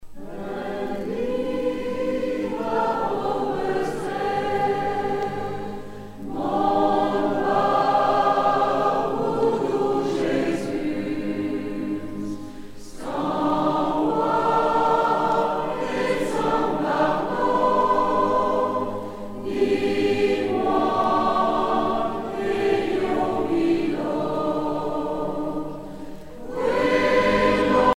circonstance : dévotion, religion ;
Pièce musicale éditée